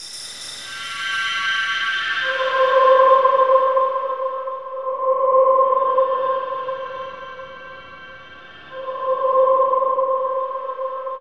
Index of /90_sSampleCDs/Chillout (ambient1&2)/13 Mystery (atmo pads)
Amb1n2_s_pad_c.wav